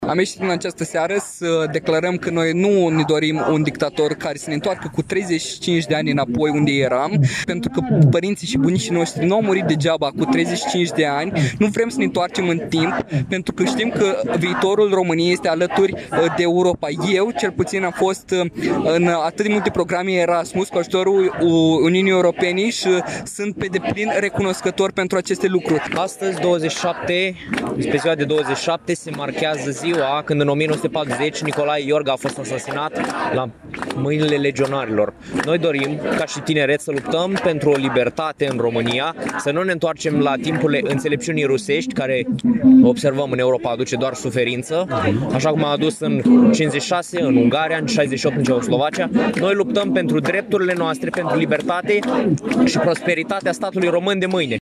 Peste 200 de tineri sunt prezenți, la această oră, în Piața Unirii din Iași pentru a-și exprima opoziția față de Călin Georgescu, candidatul independent calificat în cel de-al doilea tur de scrutin prezidențial.
27-nov-rdj-20-Vox-studenti.mp3